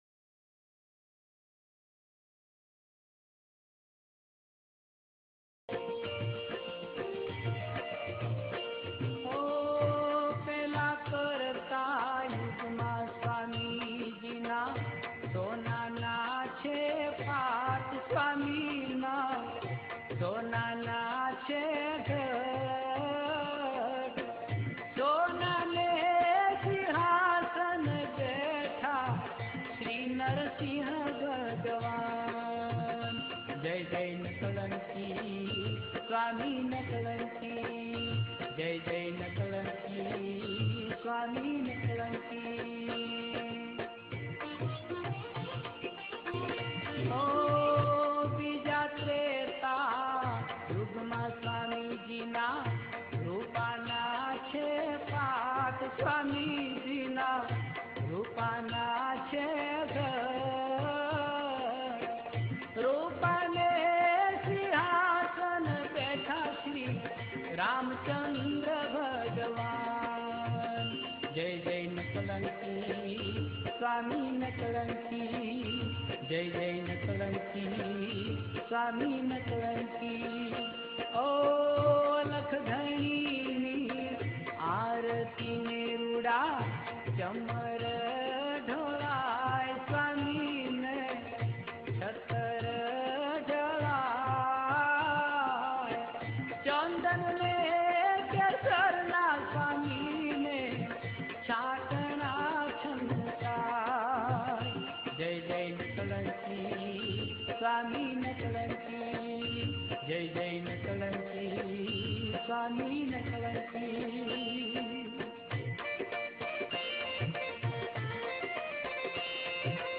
with music
3 – Aarti/Bhajan Unknown